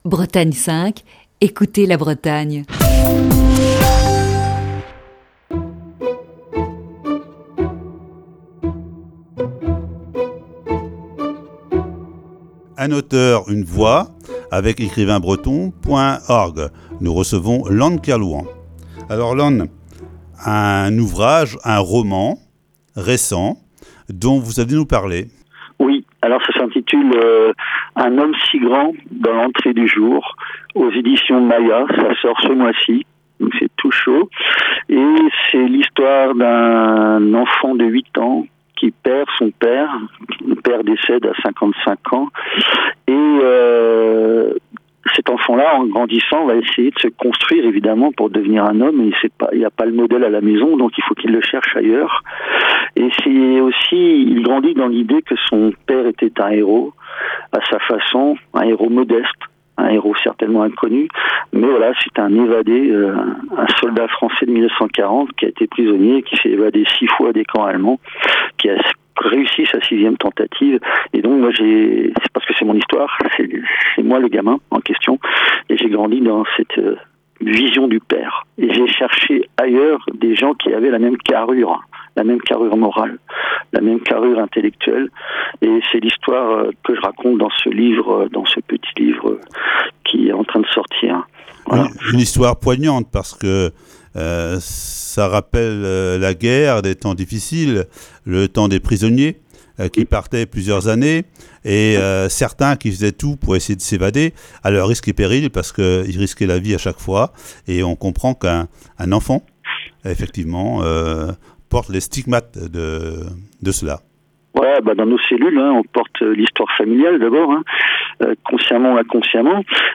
Voici ce vendredi la cinquième et dernière partie de cet entretien.